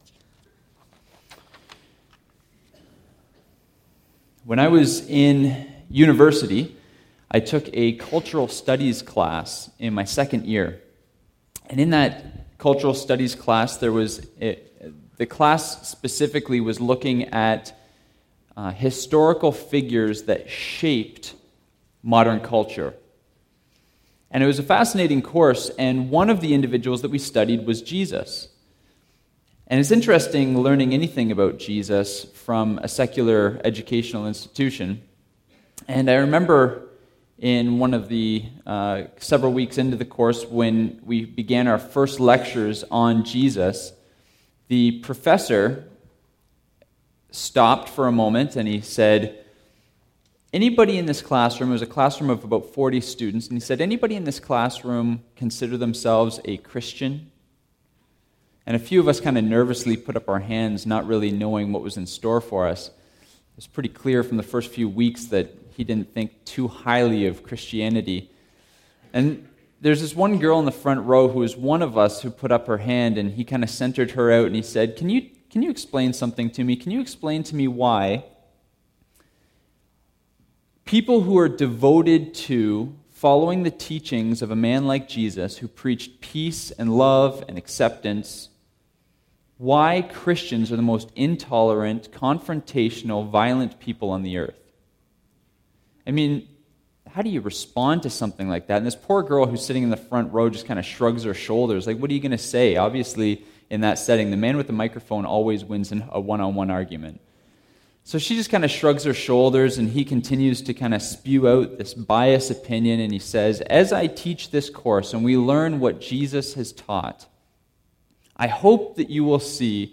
Sermon Archives - West London Alliance Church
What kind of declaration is this and what does it mean for disciples called to be peacemakers? (Our 10-week sermon series on Matthew 10 continues.)